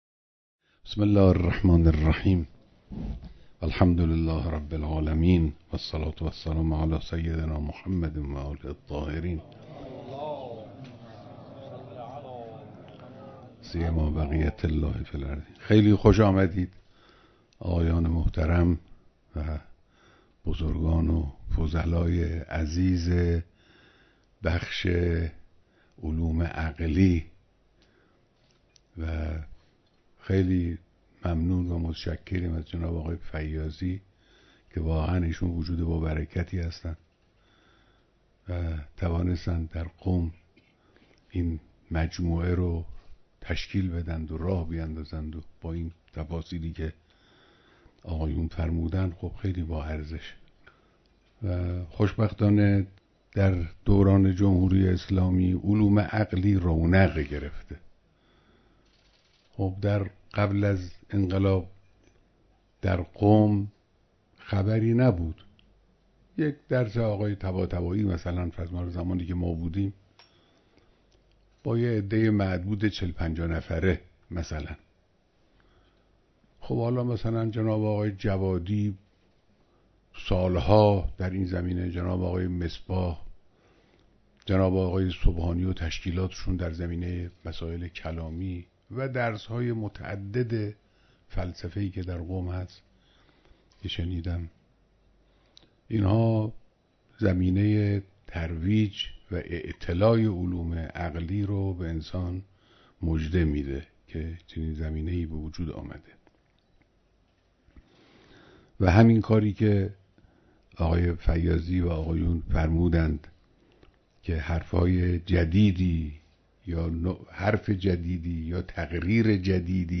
بیانات در دیدار دست‌اندرکاران همایش حکیم تهران (نکوداشت مرحوم آقاعلی مدرس زنوزی)